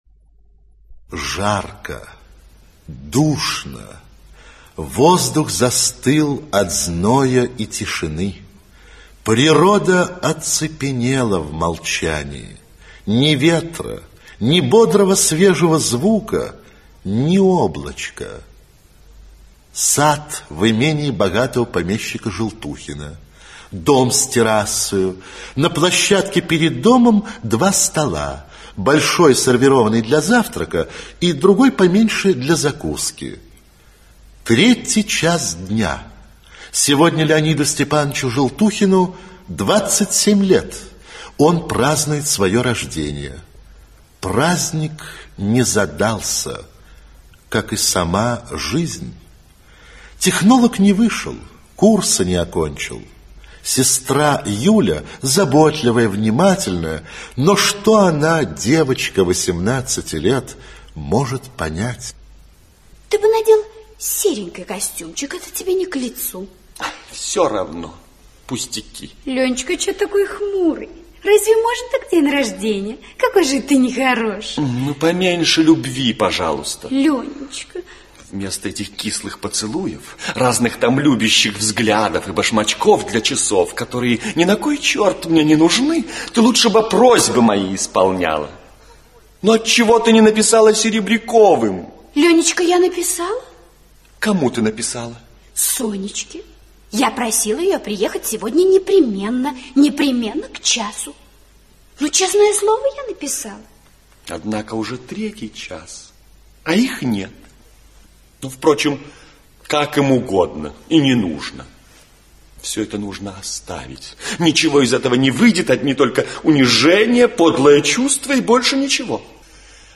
Аудиокнига Леший (спектакль) | Библиотека аудиокниг
Aудиокнига Леший (спектакль) Автор Антон Чехов Читает аудиокнигу Актерский коллектив.